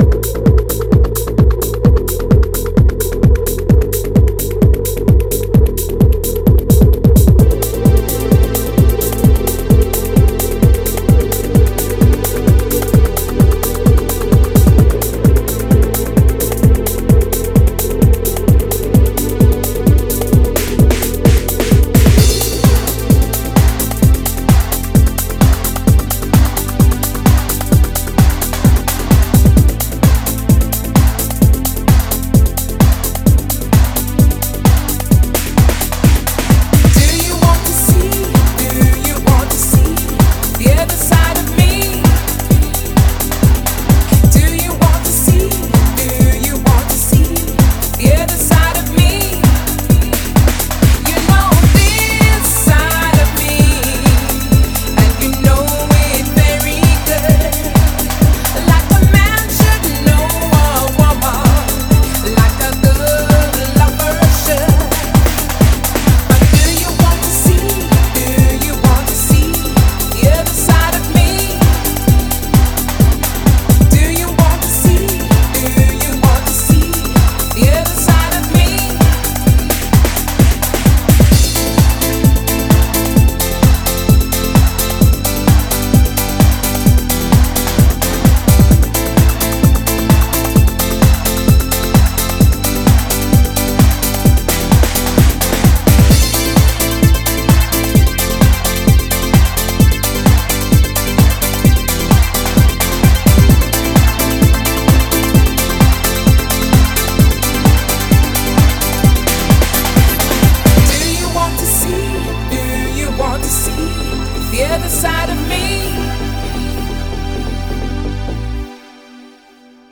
BPM130
Audio QualityPerfect (High Quality)
It's got a nice calming R&B sound to it.